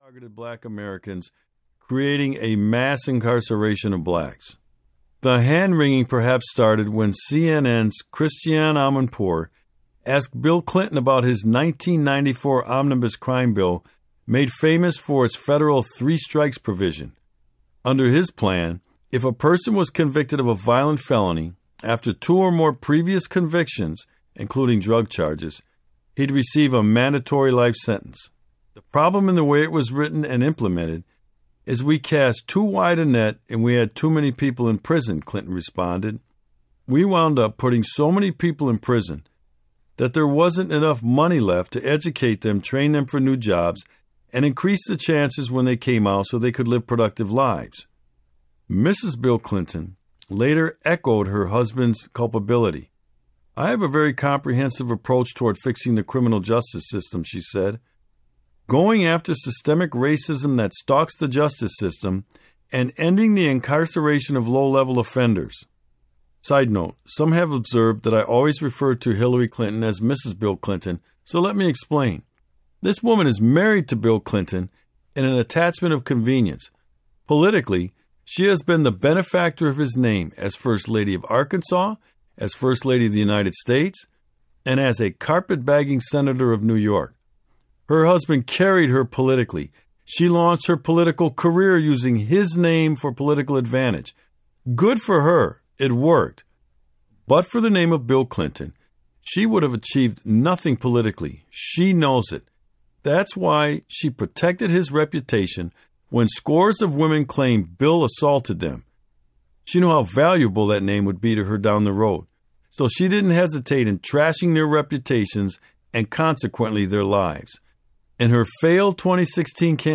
Cop Under Fire Audiobook
Sheriff David A. Clarke Jr.
7.18 Hrs. – Unabridged